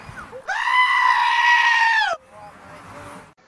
GoAt YELLL